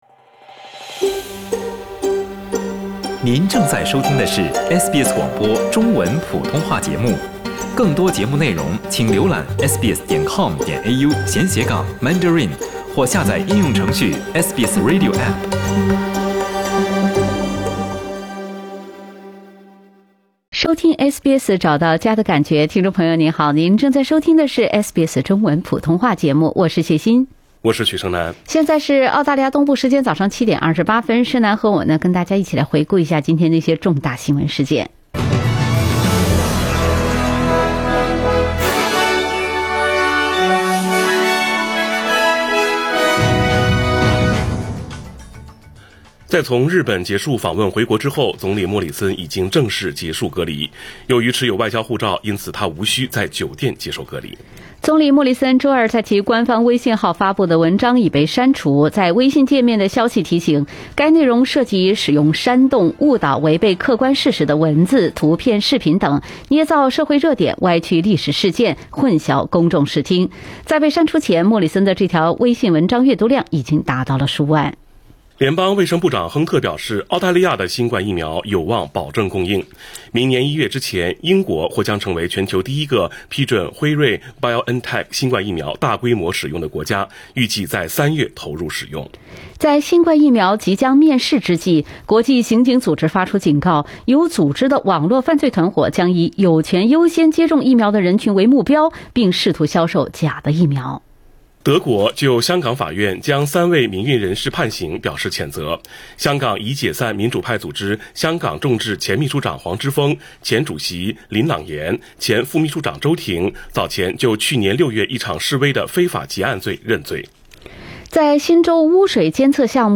SBS早新聞（12月03日）